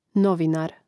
nȍvinār novinar